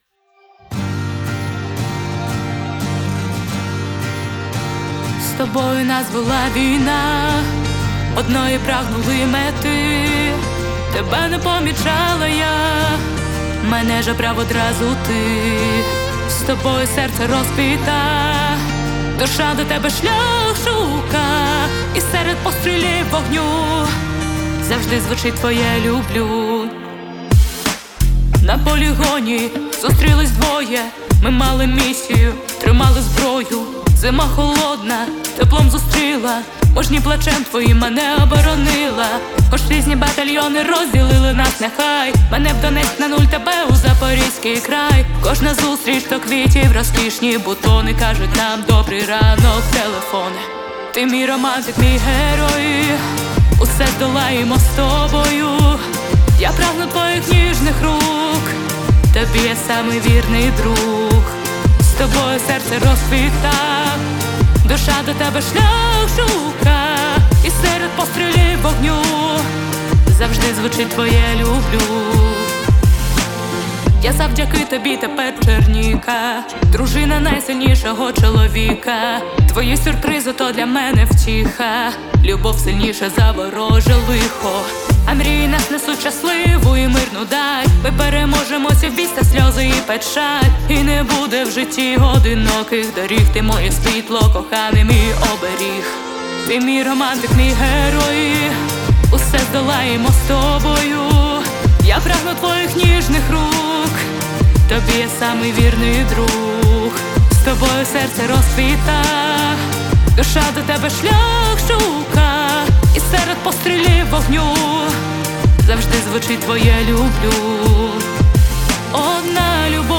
Мецо-сопрано